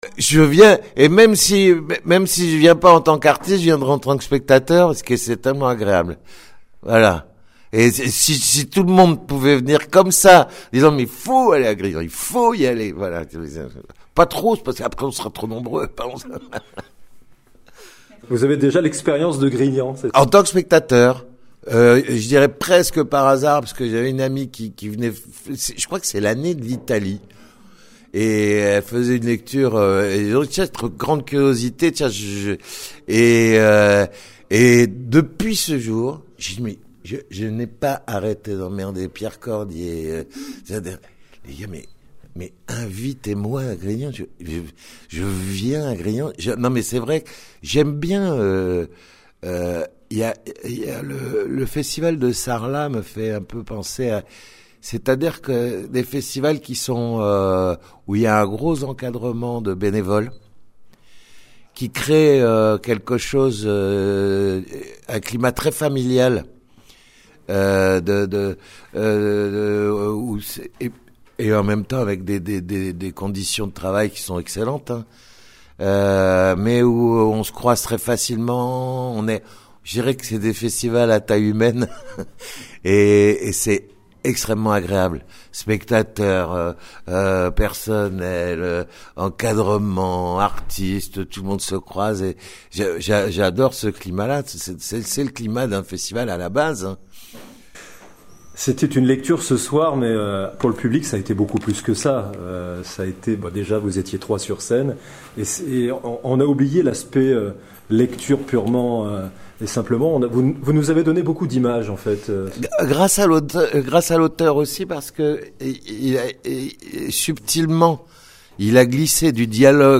En fin d’interview, les comédiens ont partagé leurs projets futurs.